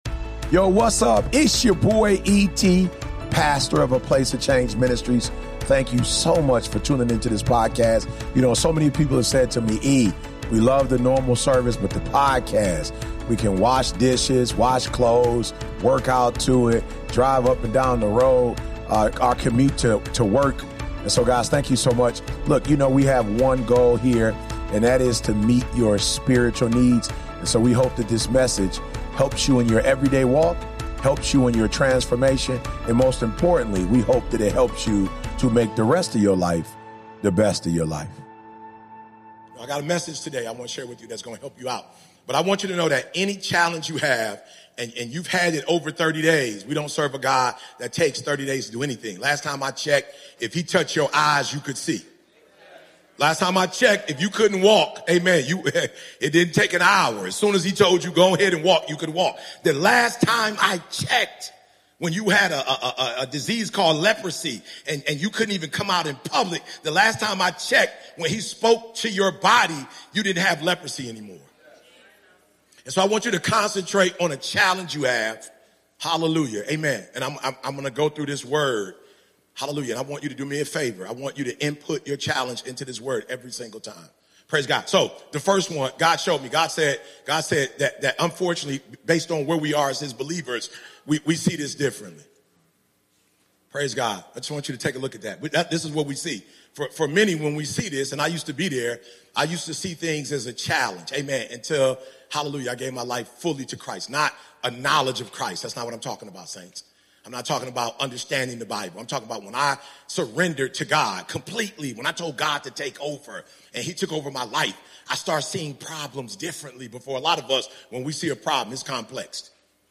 Join us for a service that's as raw as it is inspiring. Witness how mentorship, resilience, and unyielding faith can turn tragedy into triumph and transform lives. This is more than a sermon; it's a call to embrace your divine destiny and lead with an unbreakable spirit.